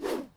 wooshSound.wav